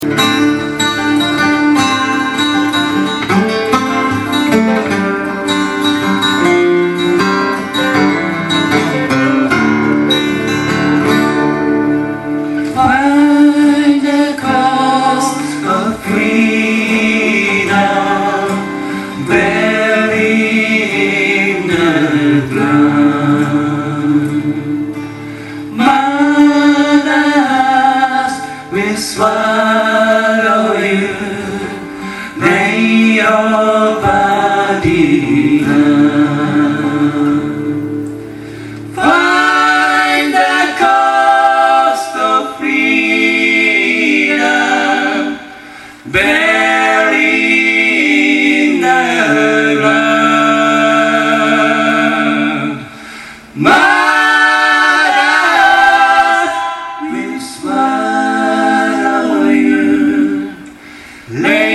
ACOUSTIC NIGHT